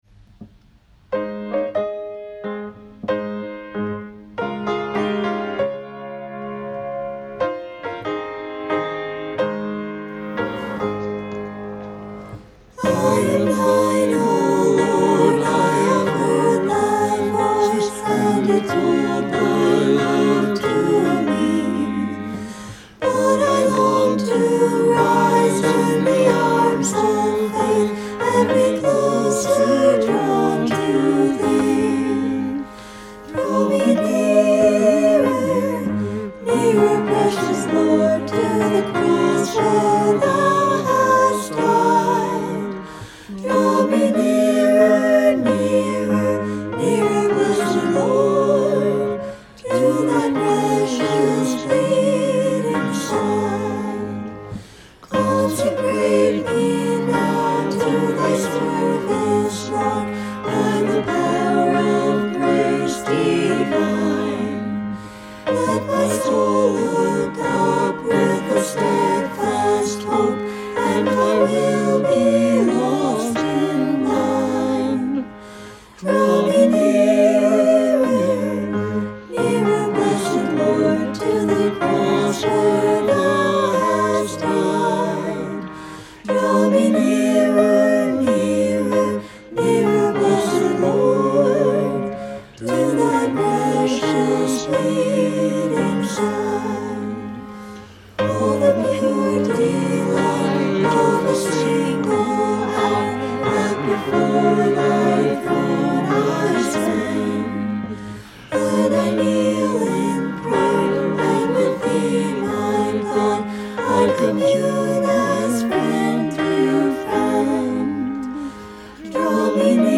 Anthem/Himno Coral: The Chancel Choir/El Coro de la Iglesia
Choral Anthem + Himno Coral
Words/Letra: Fannie Crosby + Music/Música: William H. Doane